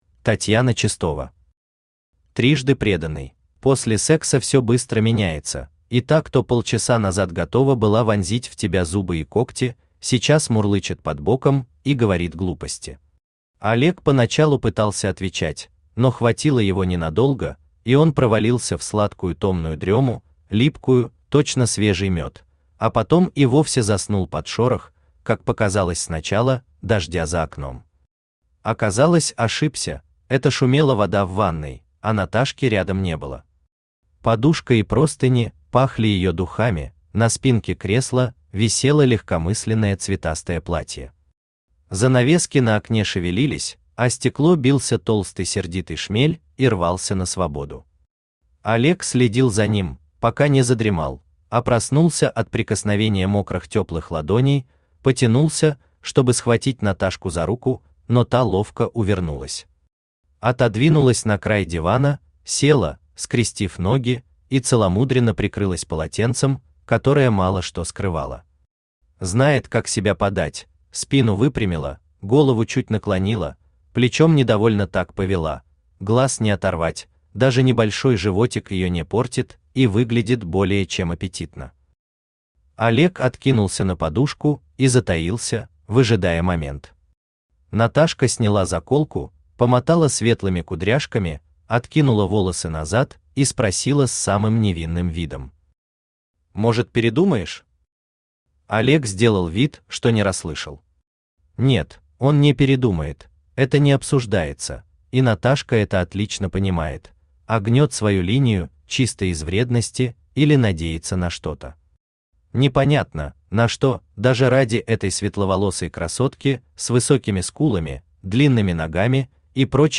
Aудиокнига Трижды преданный Автор Татьяна Чистова Читает аудиокнигу Авточтец ЛитРес.